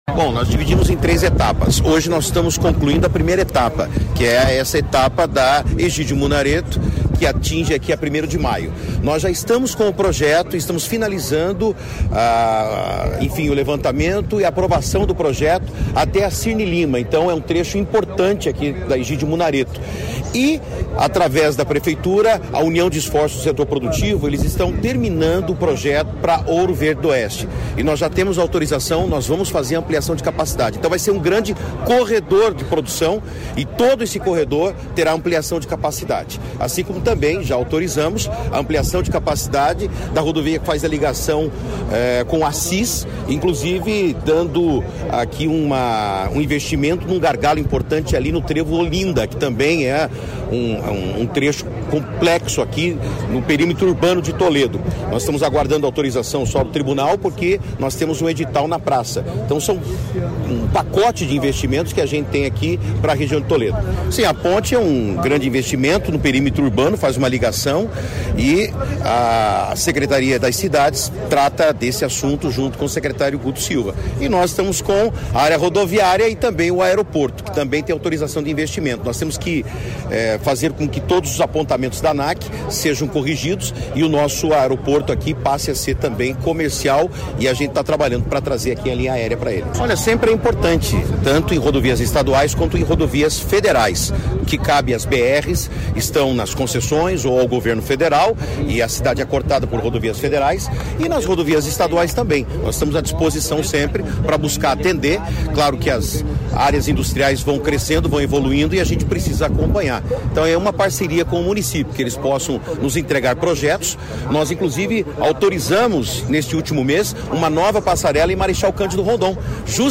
Sonora do secretário de Infraestrutura e Logística, Sandro Alex, sobre a duplicação da PR-317 e o anúncio de investimentos em Toledo